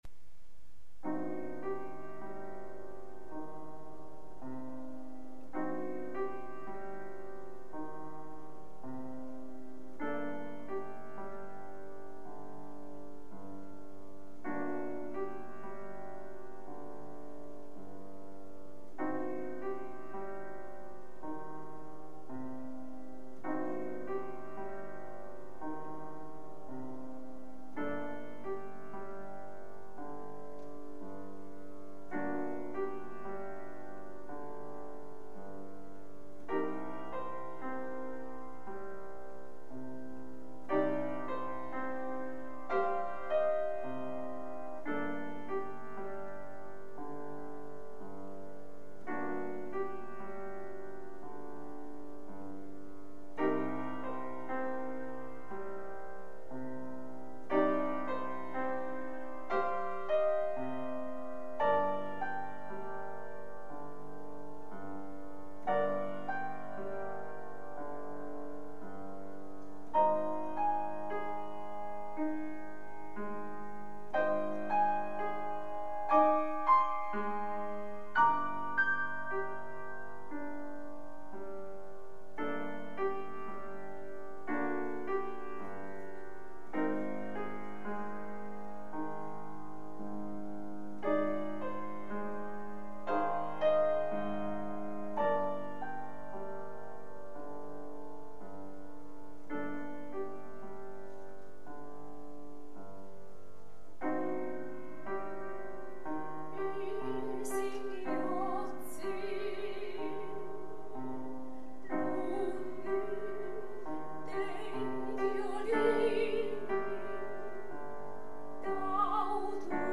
for voice and piano, or for voice and harp
(per canto e pianoforte, o canto ed arpa)